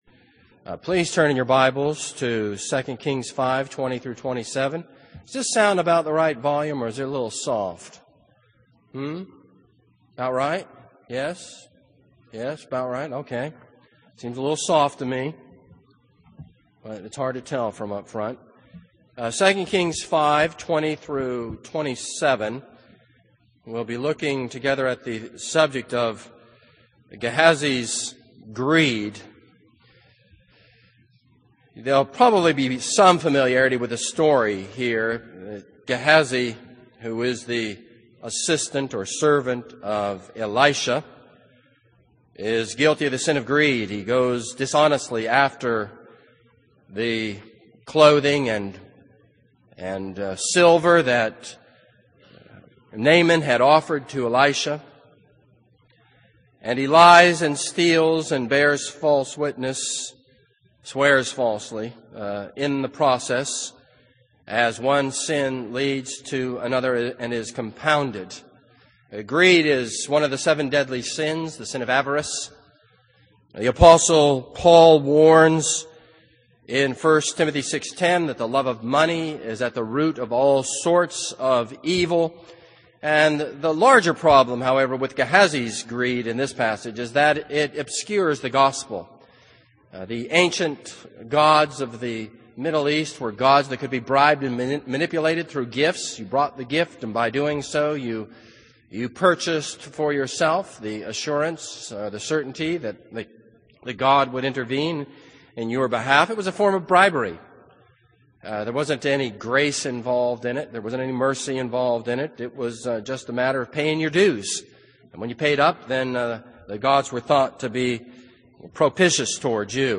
This is a sermon on 2 Kings 5:20-27.